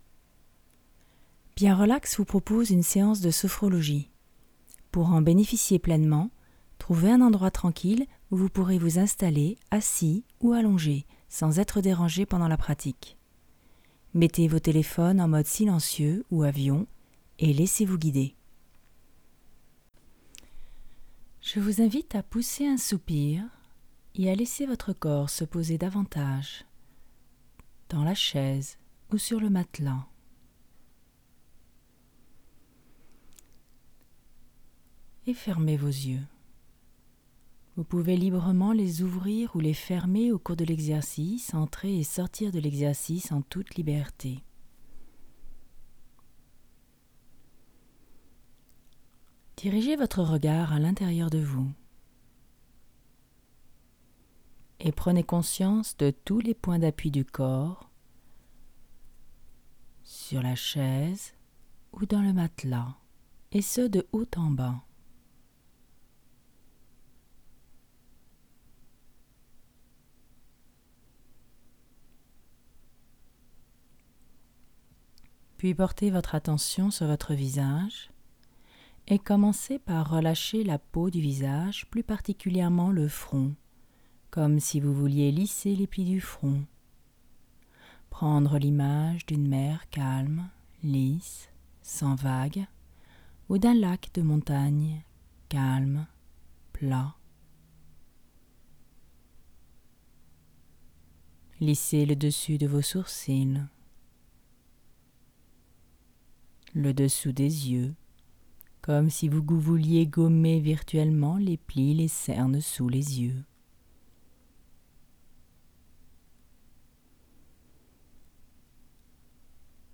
Genre : Sophrologie